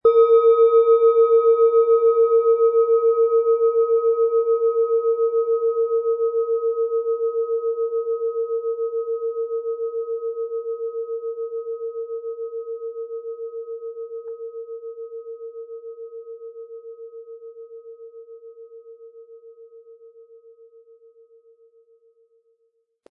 Planetenschale® antik Sensibel und Einfühlend sein & In den Bauch spüren mit Mond, Ø 11,5 cm, 320-400 Gramm inkl. Klöppel
Der Klang bleibt dabei klar, warm und harmonisch - getragen von all den Erfahrungen, die in dieser Schale schwingen.
Diese Planetenschale mit ihrem tiefen, sanften Mond-Ton lädt dich ein, in dich hinein zu lauschen.
Um den Originalton der Schale anzuhören, gehen Sie bitte zu unserer Klangaufnahme unter dem Produktbild.
Sanftes Anspielen mit dem gratis Klöppel zaubert aus Ihrer Schale berührende Klänge.